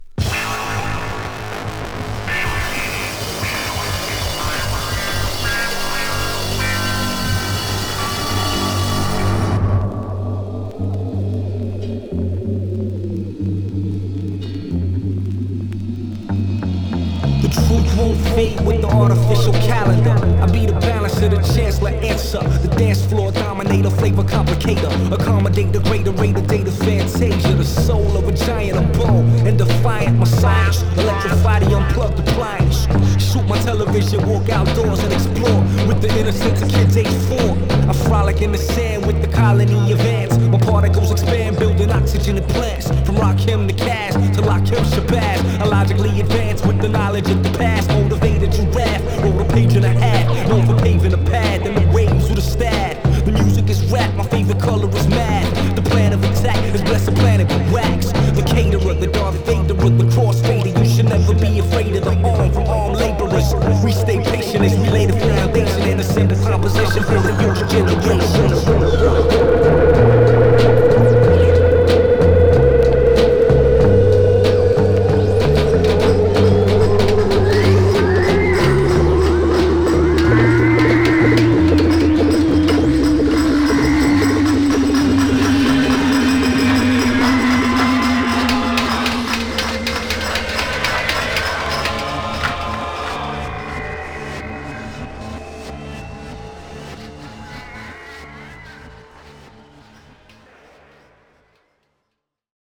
類別 饒舌、嘻哈